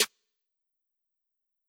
Snare Youza 2.wav